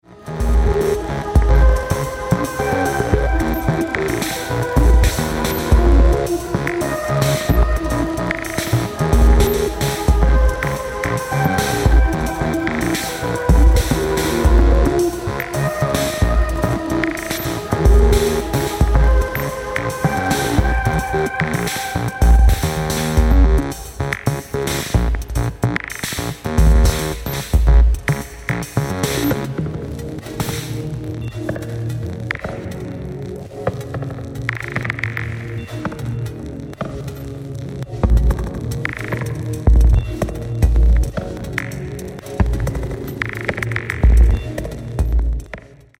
with a brand new slab of dark techno funk
The a side is pretty darn banging, and thats a good thing.